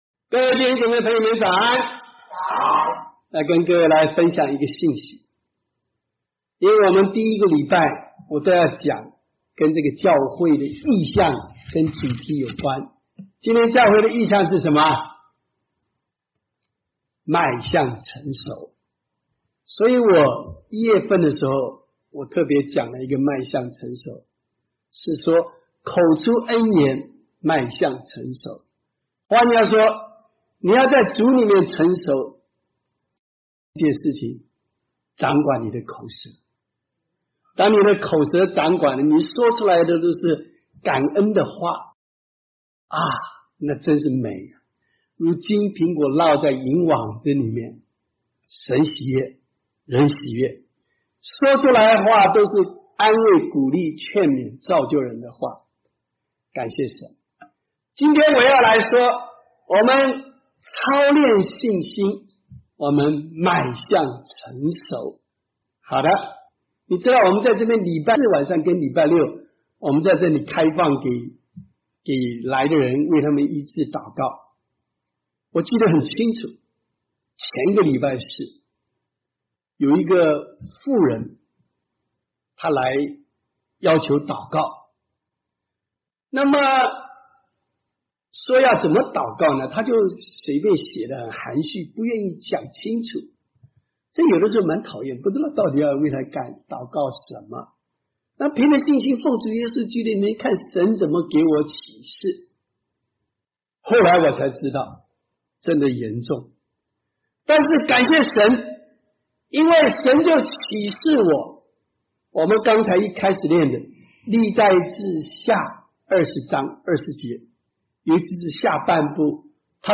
場所：主日崇拜